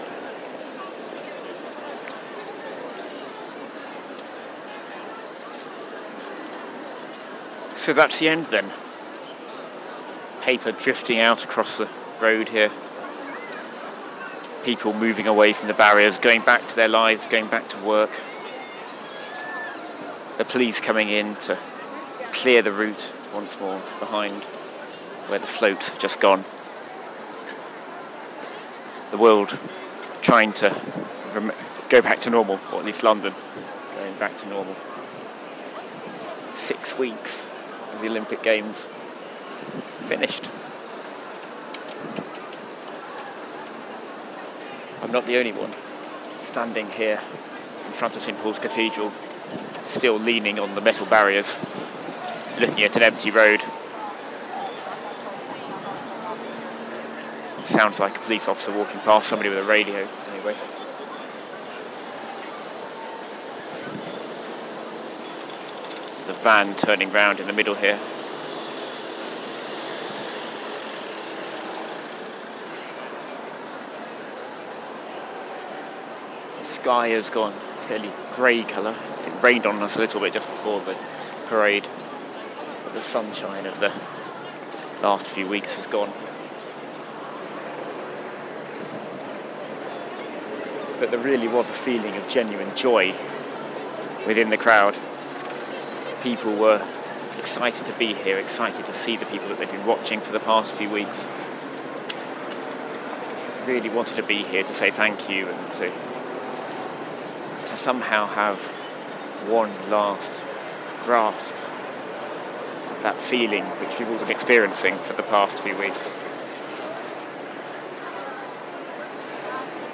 Watching the crowds drift away after London's magical summer of sport.